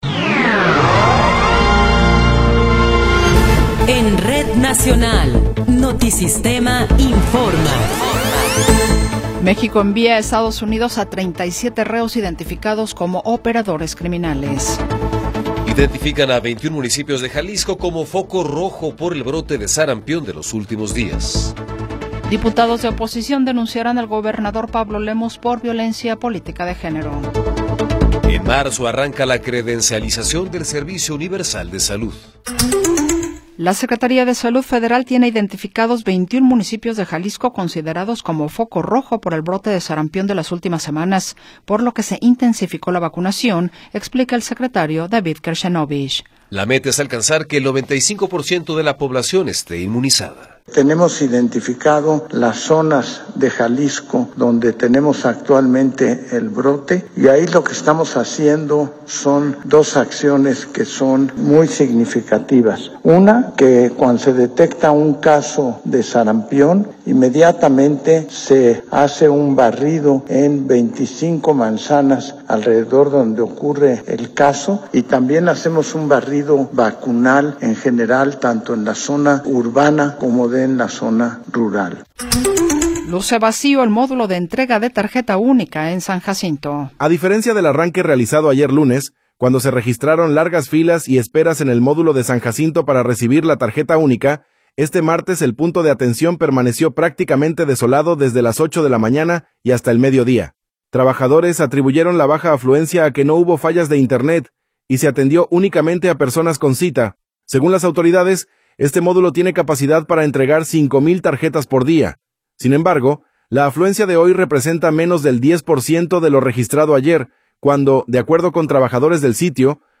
Noticiero 14 hrs. – 20 de Enero de 2026
Resumen informativo Notisistema, la mejor y más completa información cada hora en la hora.